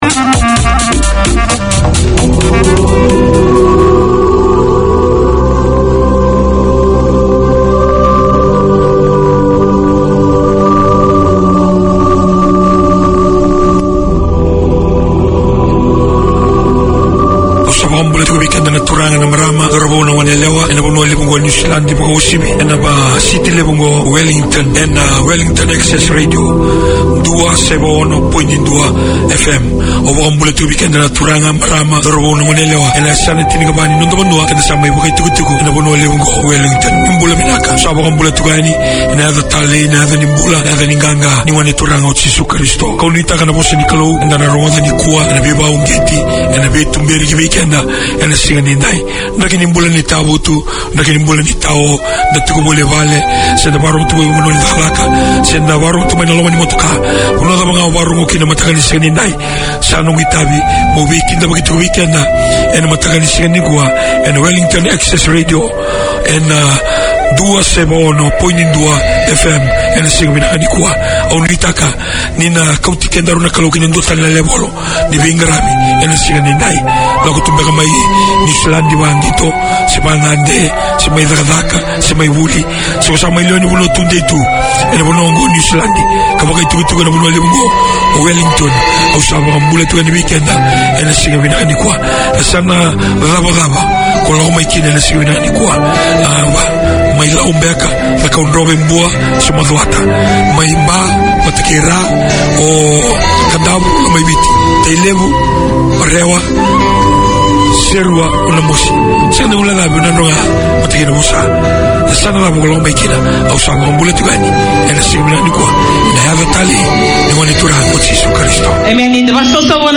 This show talks about science topics and their relevance to our everyday lives in a language that is understandable to the person on the street. Fascinating discussions are delivered along with ‘hot-off-the-press’ science news and a curious selection of the favourite music of scientists.